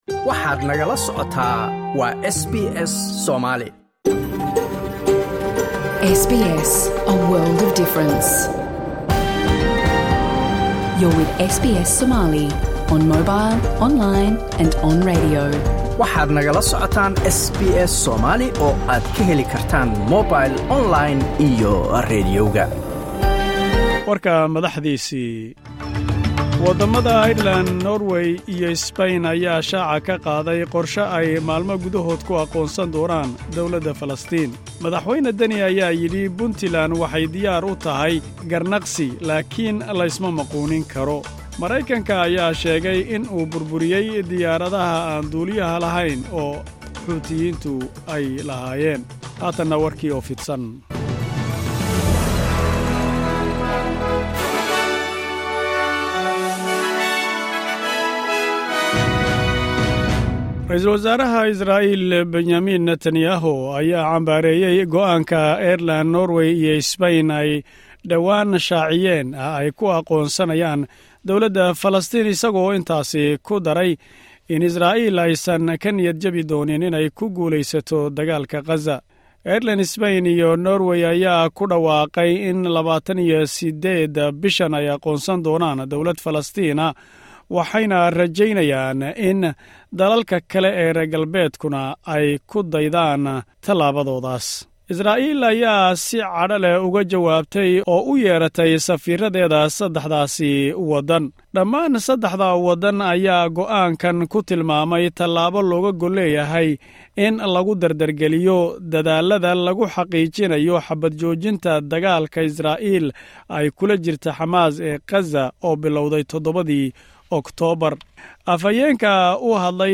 WARARKA SBS SOMALI, 23 MAY.
Wararka Laanta Afka Soomaaliga ee SBS (Australia).